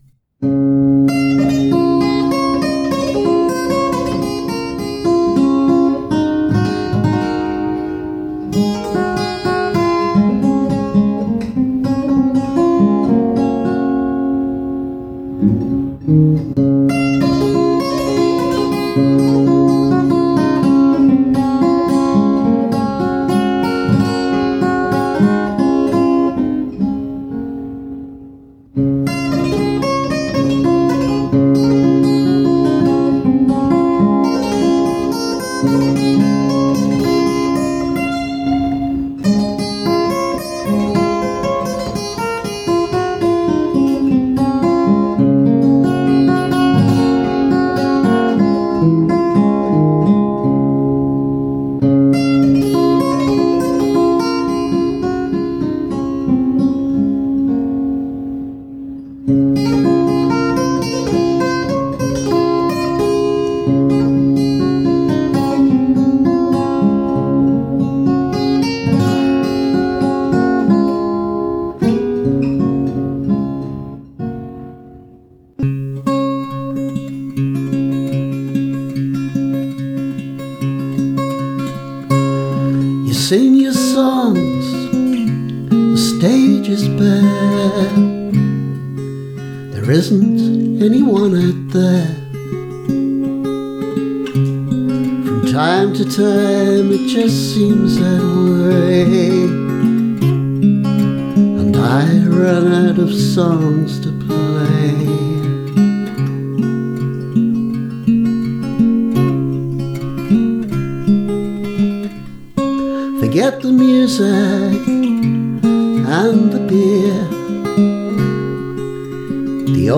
This version has a slightly trimmed version of the lengthy instrumental (much more recent) with which it starts, and a different take on the actual song.
Unusually for me, the guitar is in open G (which I did use a lot more in those days, but mostly for slide).